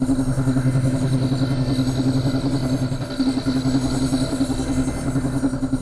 tank_skill_shieldrush.wav